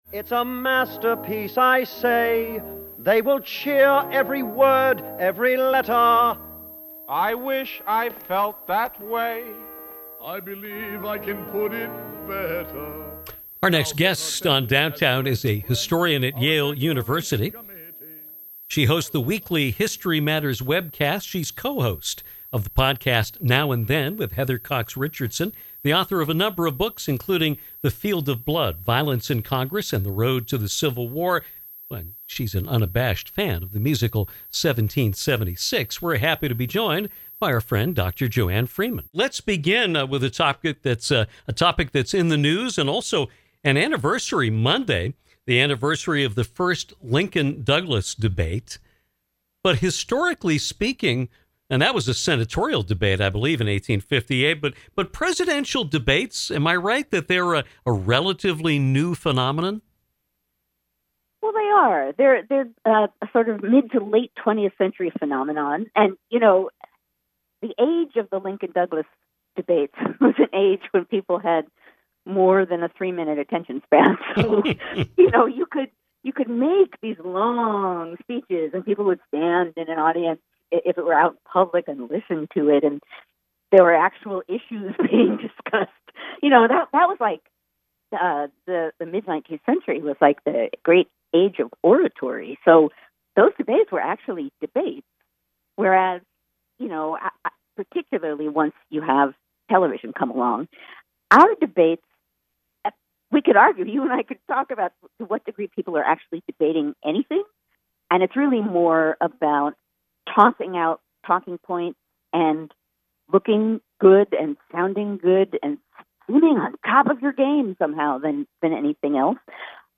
Yale historian, author, and podcast host Dr. Joanne Freeman returned to the show this afternoon to discuss a wide range of topics, from freedom of the press to political violence, women’s sports, and Broadway musicals.